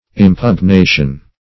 Search Result for " impugnation" : The Collaborative International Dictionary of English v.0.48: Impugnation \Im`pug*na"tion\, n. [L. impugnatio: cf. OF. impugnation.]